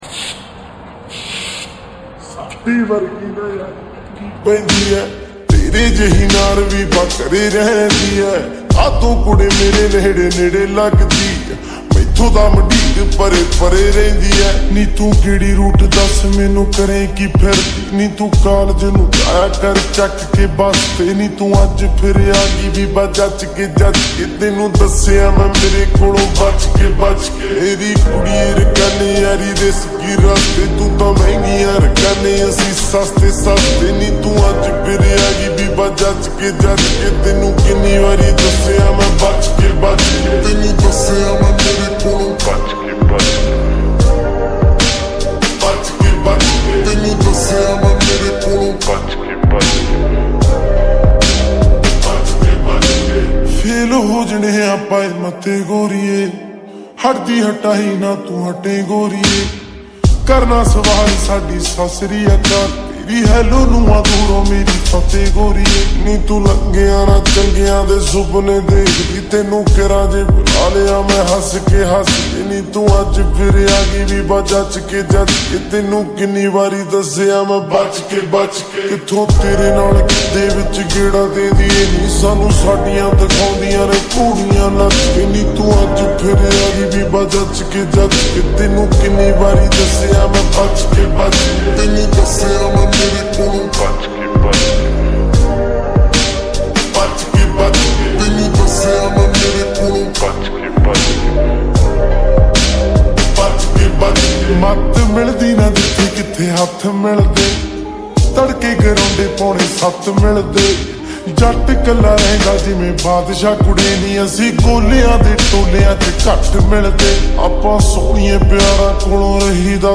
Slowed Reverb.